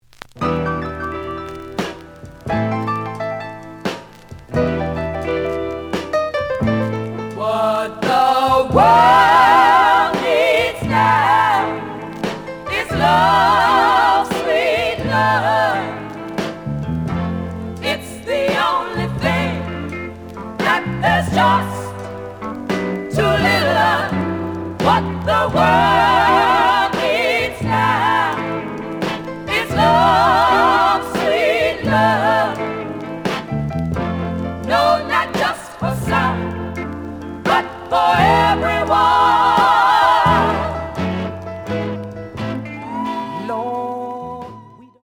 ●Genre: Soul, 60's Soul
Looks good, but slight noise on A side.)